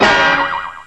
bamboing.wav